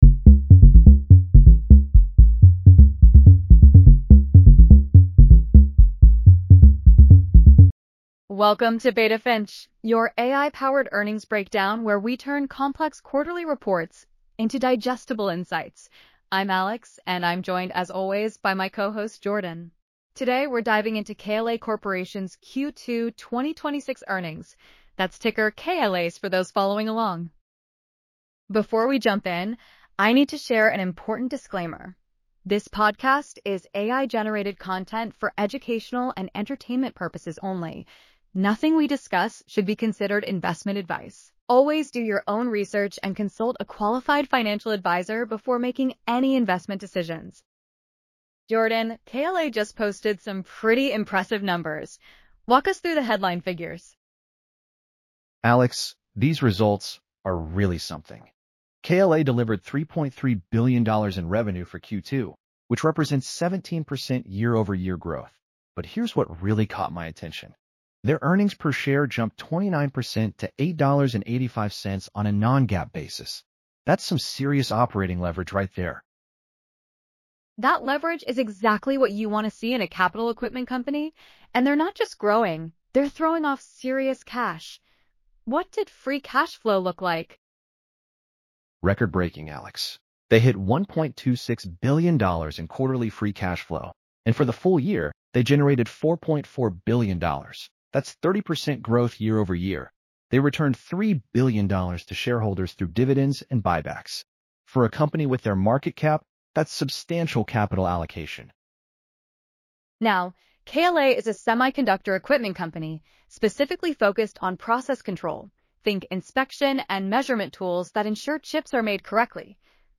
BETA FINCH PODCAST SCRIPT